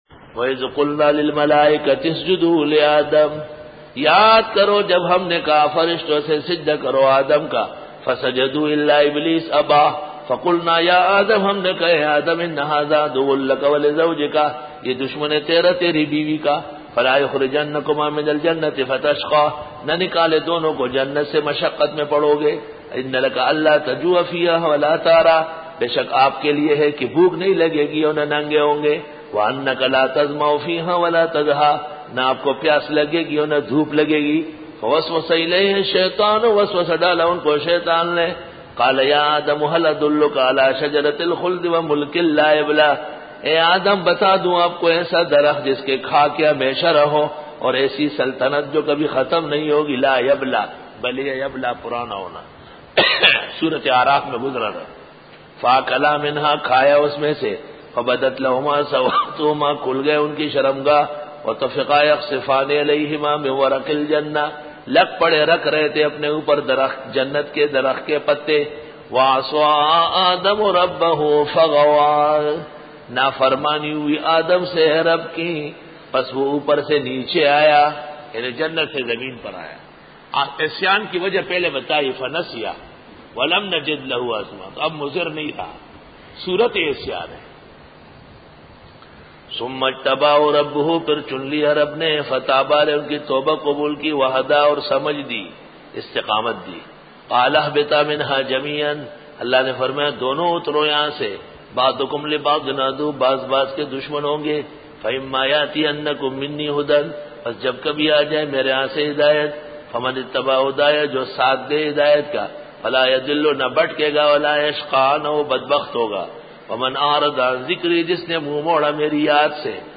سورۃ طہ رکوع-07 Bayan